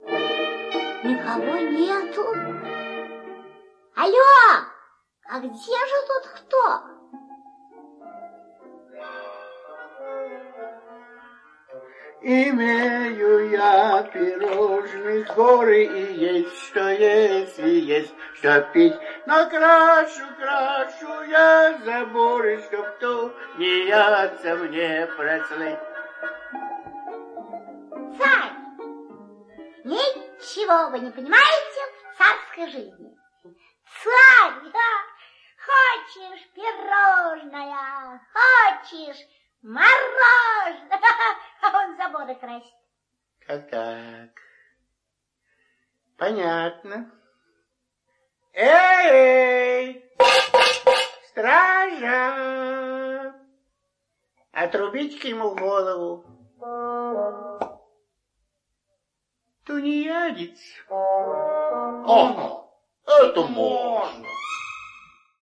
Мелодия для детей простая и понятная для всех.